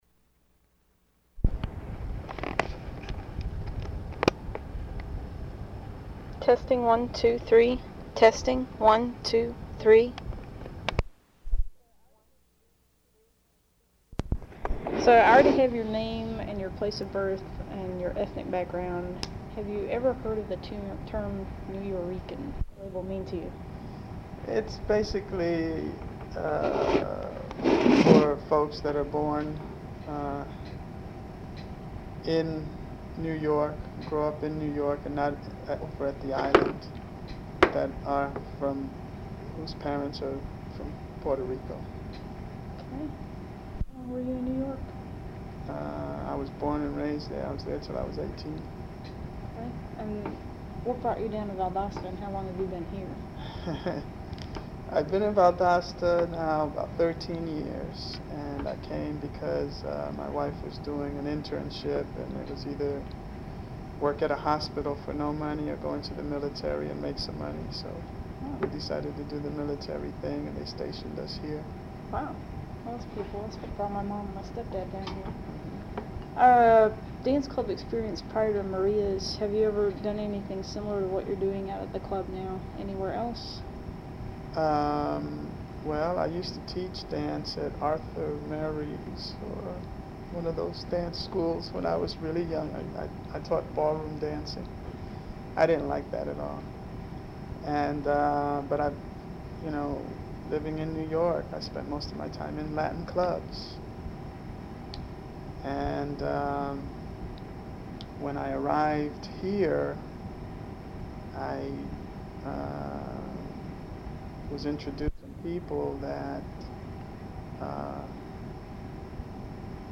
Valdosta (Ga.).